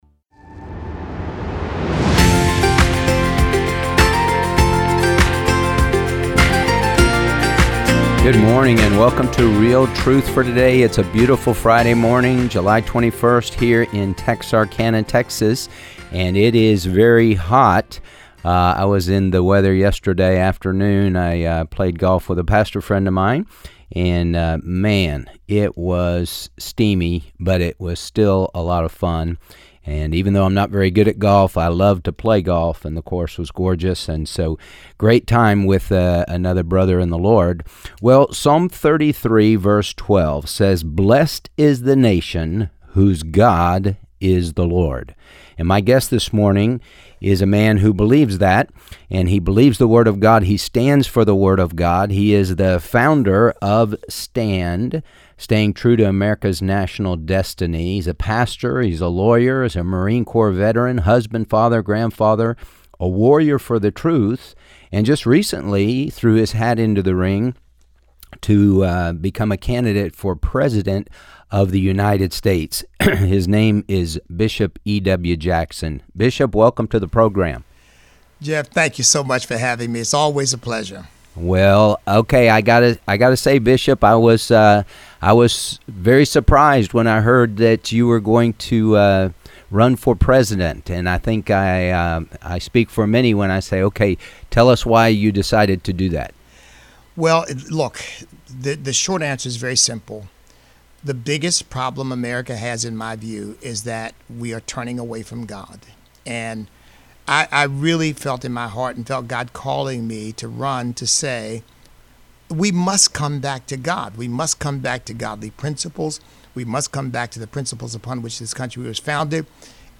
A Conversation with Bishop E.W. Jackson